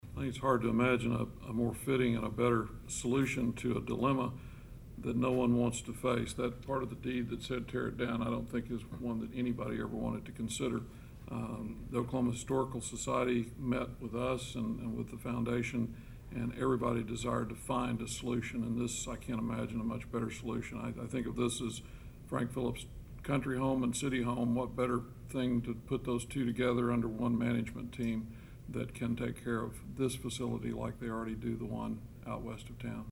Mayor Dale Copeland is very satisfied that a solution to this difficult problem was reached.